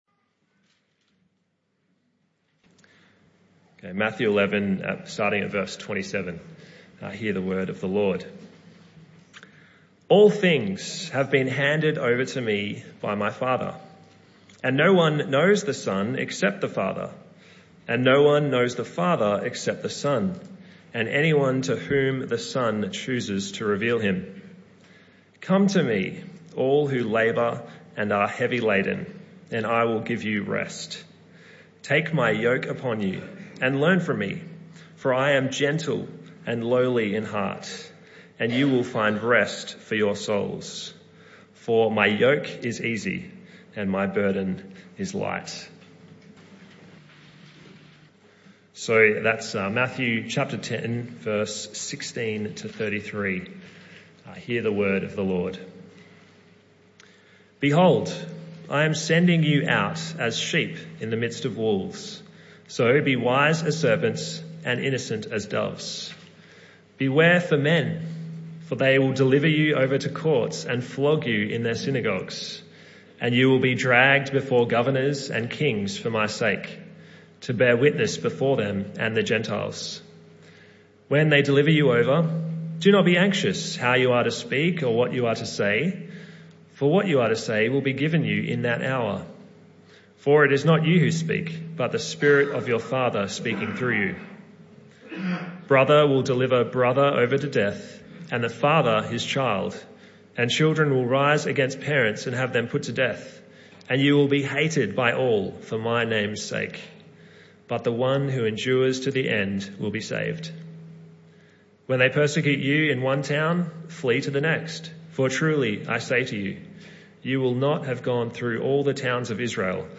This talk was part of the AM Service series entitled Vine Project Themes (Talk 2 of 5).